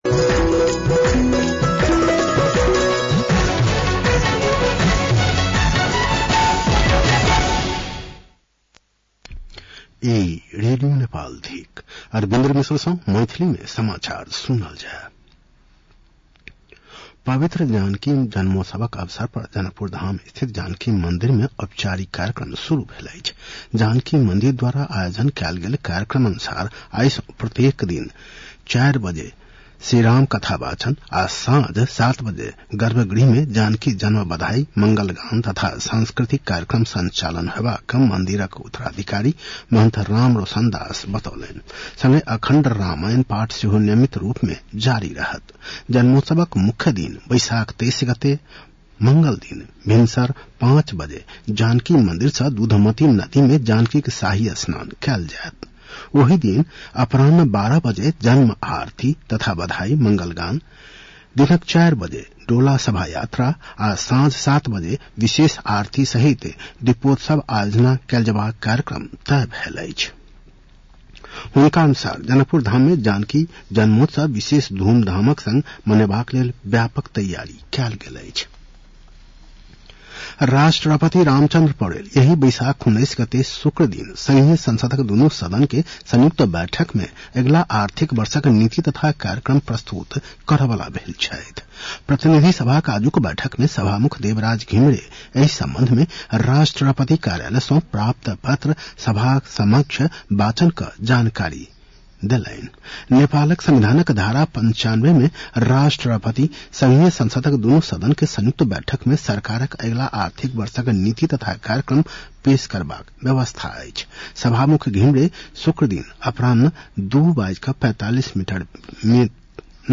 मैथिली भाषामा समाचार : १६ वैशाख , २०८२